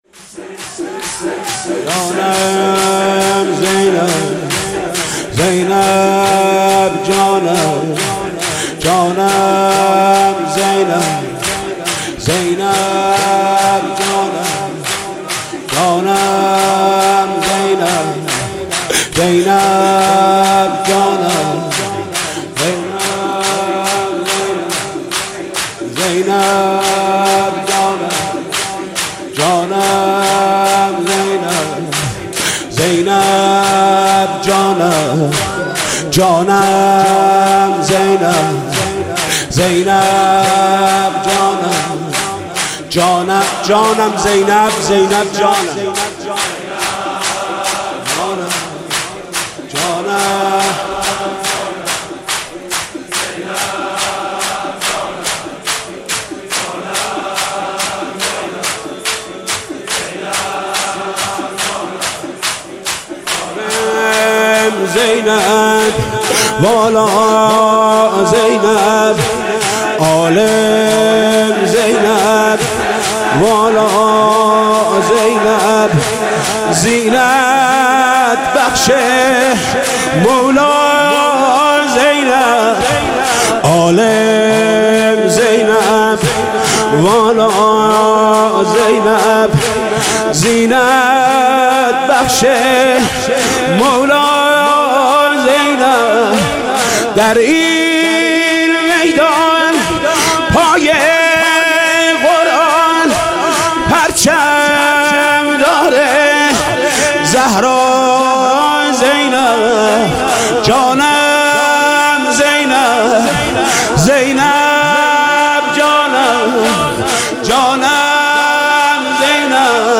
شب چهارم محرم - دوطفلان حضرت زینب سلام الله علیها
محرم 95 | شور | عالم زینب والا زینب
حاج محمود کریمی 95